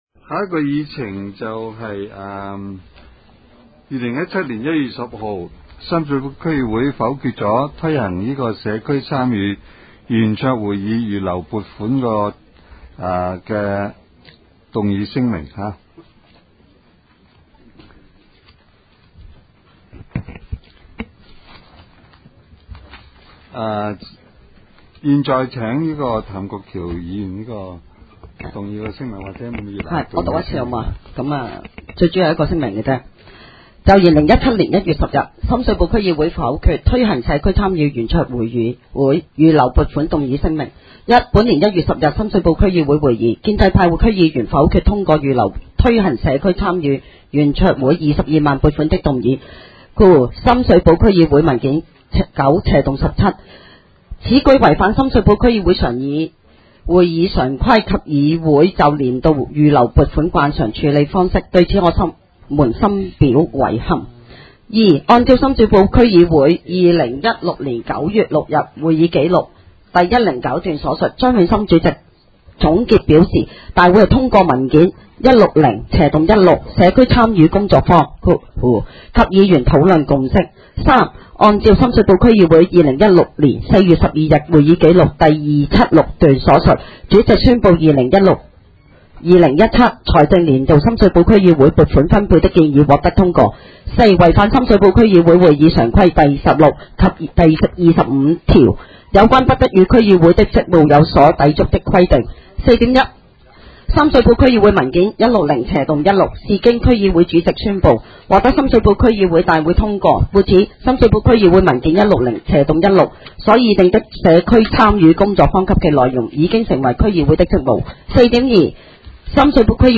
区议会大会的录音记录
深水埗区议会第八次会议
深水埗区议会会议室